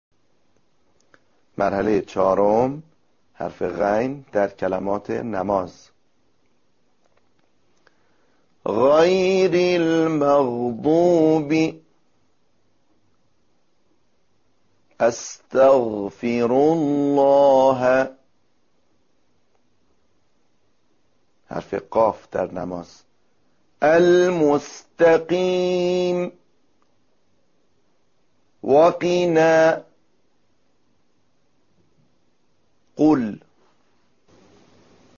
چنانچه هنگام عبور هوا از این تنگه ٬ تارهای صوتی نیز به ارتعاش درآید صدای حرف «غ» تولید می شود.
تمرین عملی_مرحله ۴
💠تلفظ حرف «غ»💠